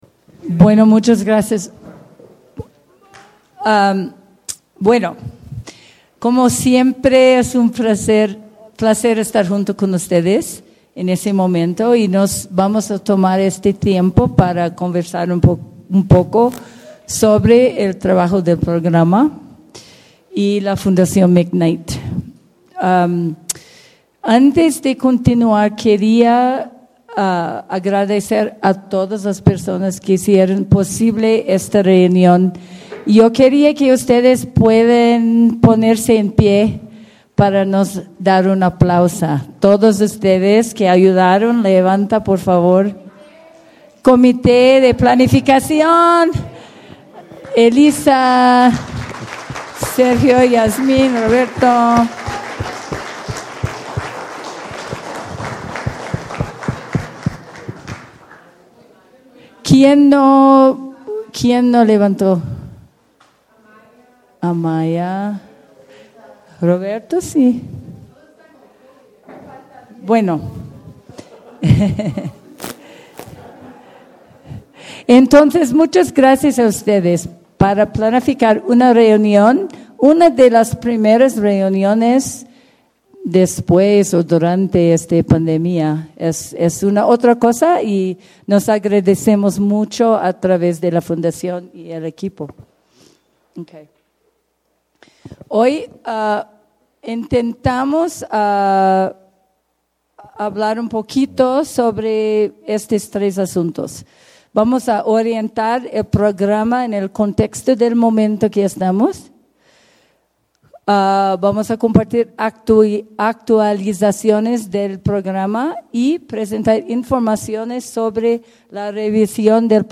Presentación de la Fundación McKnight – Andes CDP
Presentacion_Fundacion_McKnight.mp3